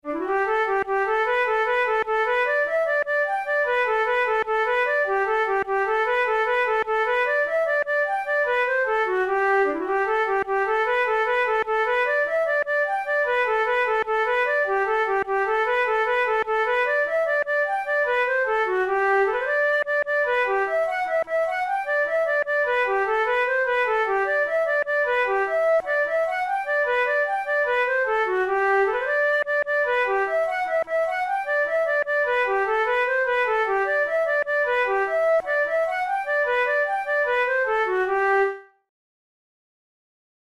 InstrumentationFlute solo
KeyG major
Time signature6/8
Tempo100 BPM
Jigs, Traditional/Folk
Traditional Irish jig